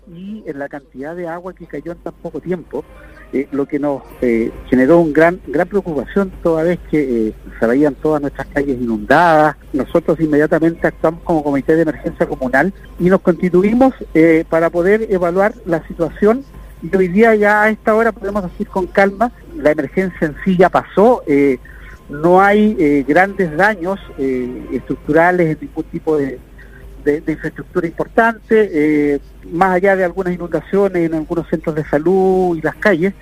Situación compleja, según el alcalde Hardy Vásquez, quien entregó el balance luego del COE comunal.